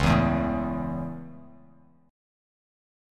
C#m#5 chord